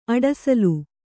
♪ aḍasalu